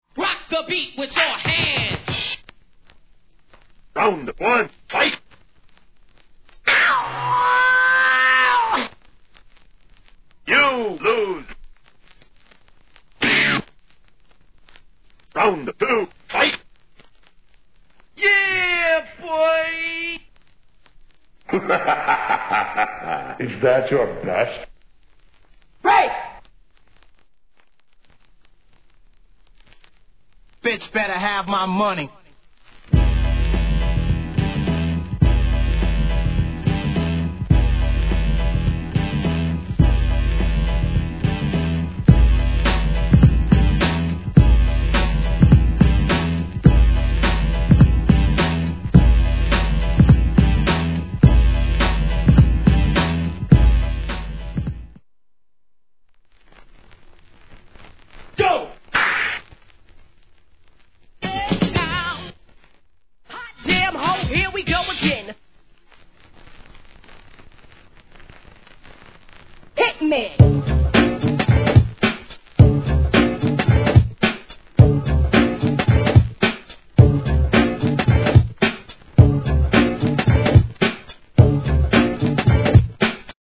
HIP HOP/R&B
バラエティーに飛んだ声ネタからBEATを集めた'90s BATTLE TOOL!!